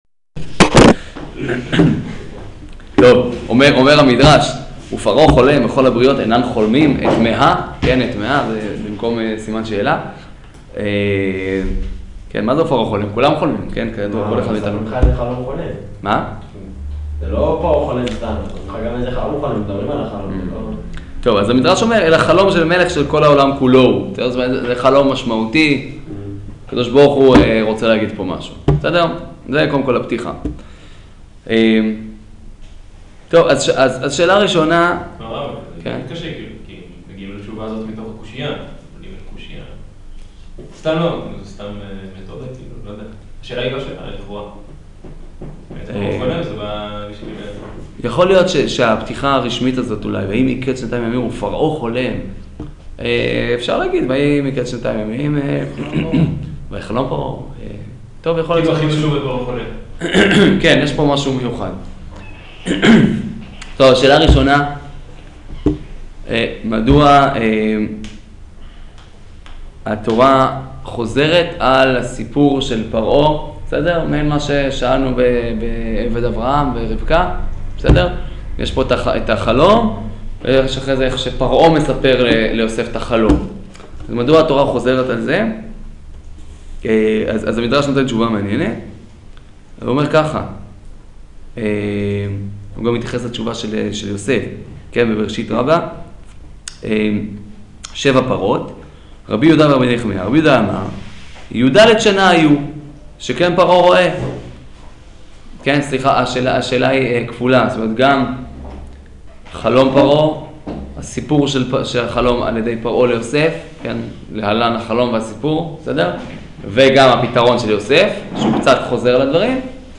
שיעור פרשת מקץ - חלום פרעה